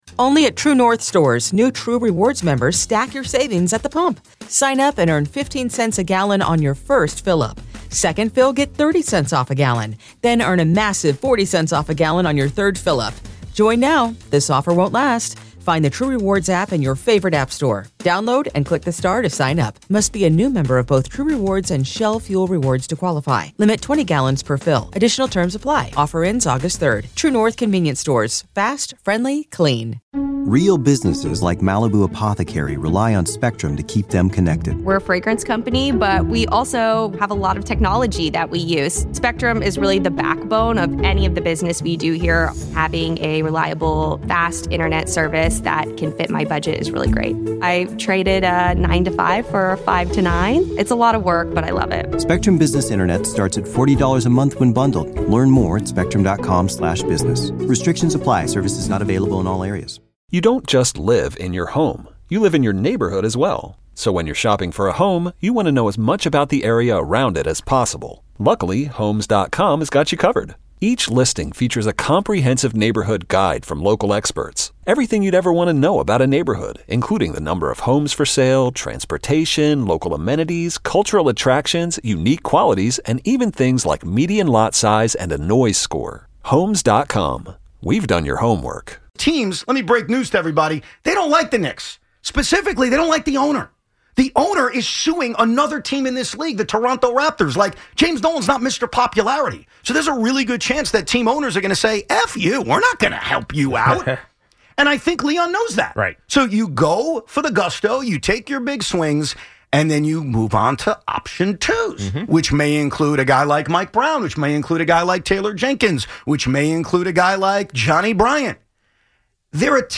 get into a heated argument in the open about the Knicks coaching plan